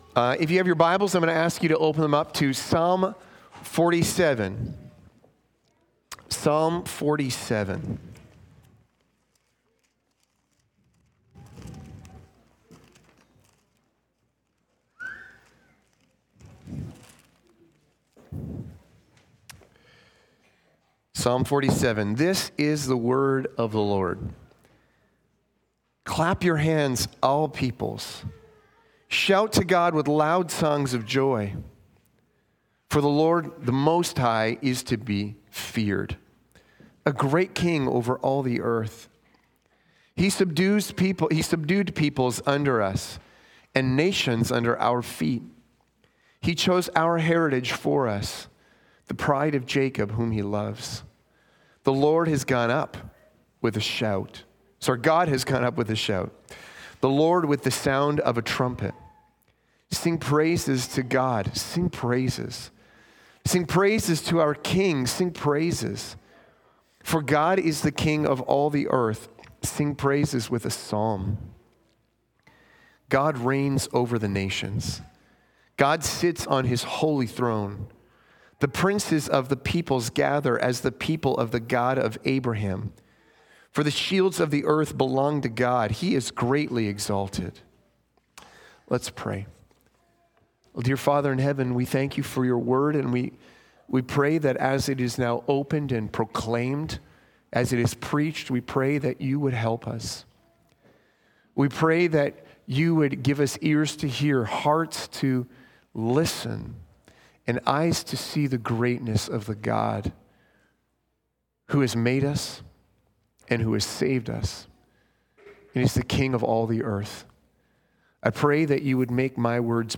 Sermon Title:He is Ascended, He is Ascended Indeed!Sermon Outline: